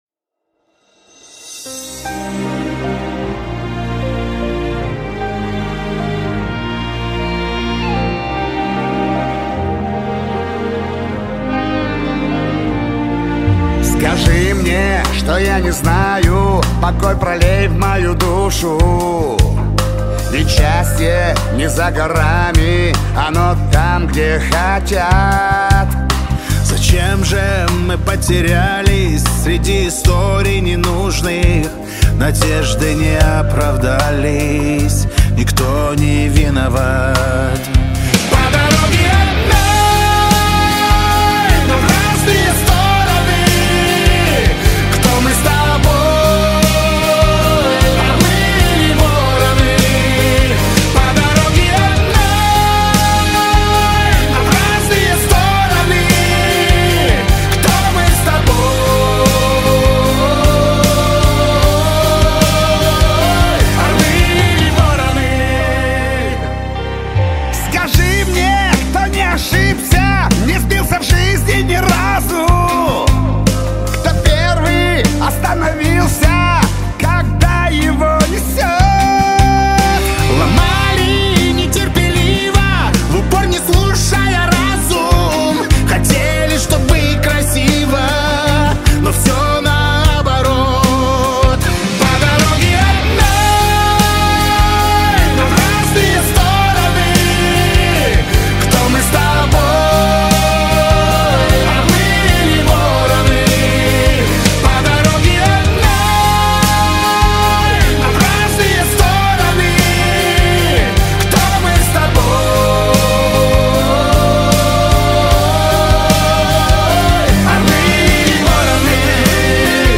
Категория: Рок музыка
рок песни